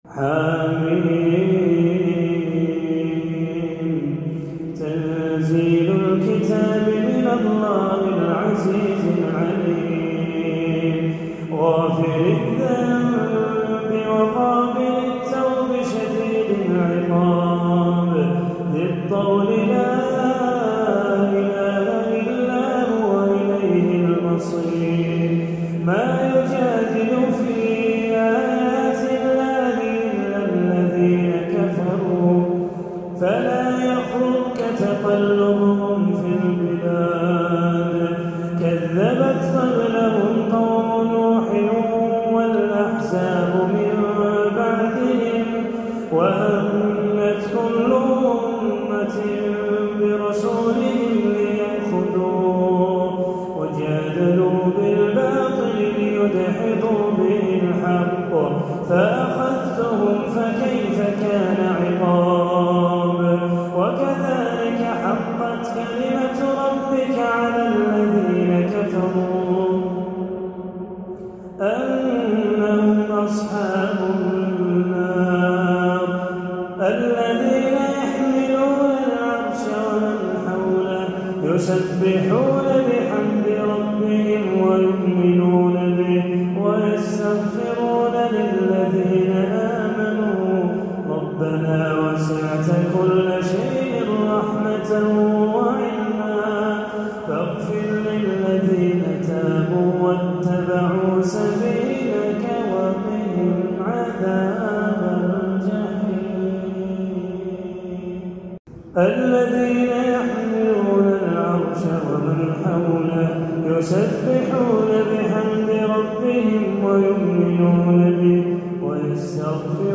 عشائية جميلة